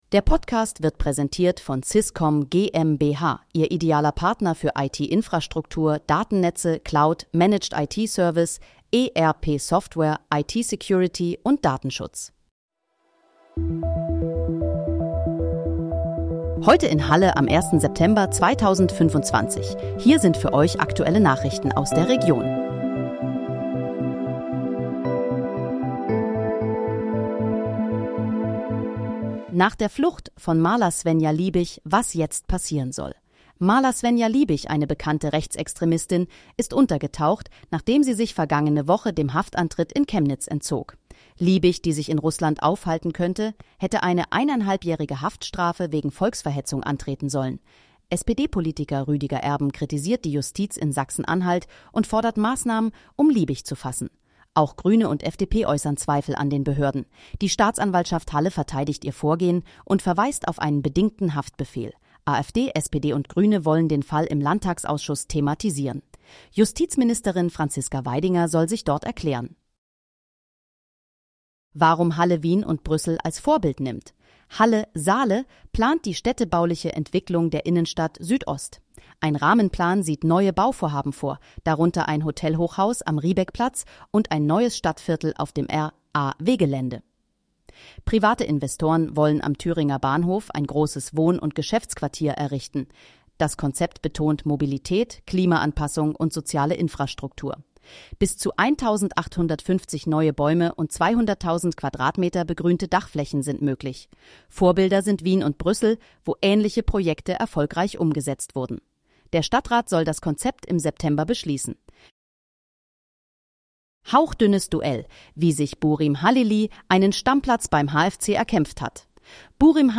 Heute in, Halle: Aktuelle Nachrichten vom 01.09.2025, erstellt mit KI-Unterstützung
Nachrichten